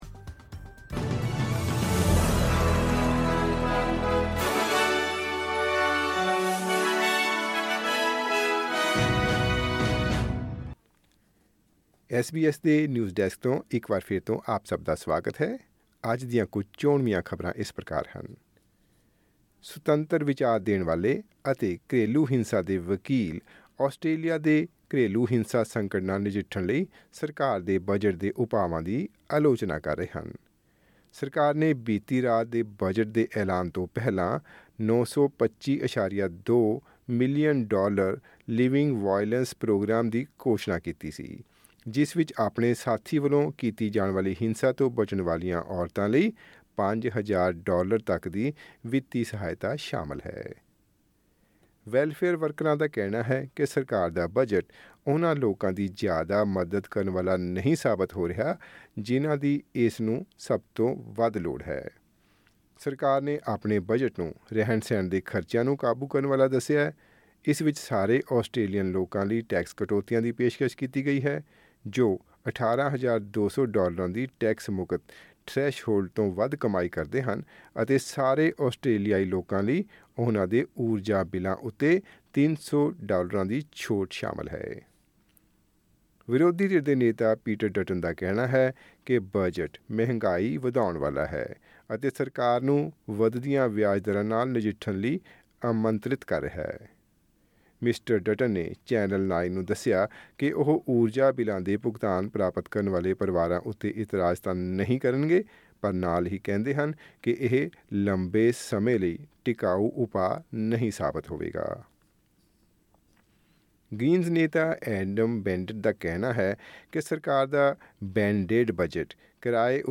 ਐਸ ਬੀ ਐਸ ਪੰਜਾਬੀ ਤੋਂ ਆਸਟ੍ਰੇਲੀਆ ਦੀਆਂ ਮੁੱਖ ਖ਼ਬਰਾਂ: 15 ਮਈ, 2024